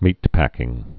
(mētpăkĭng)